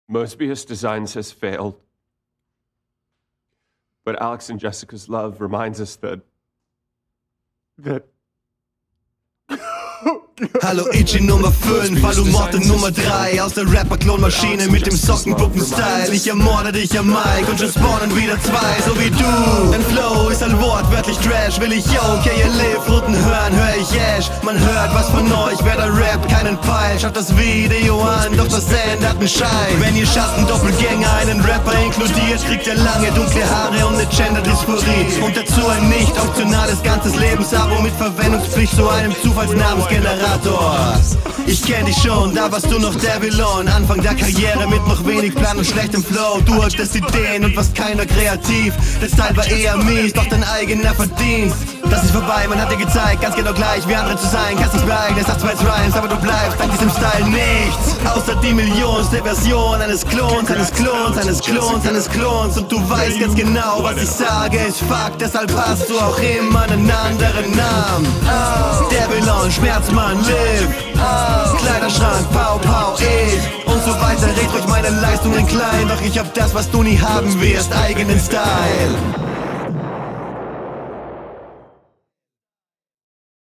Der Beat ist absolut bodenlos, der Flow entsprechend, der Mix ist überraschend gut für den …
Der Beat ist schon fragwürdig, aber das da die ganze Zeit irgendwelche weirden Vocals im …